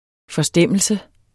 Udtale [ fʌˈsdεmˀəlsə ]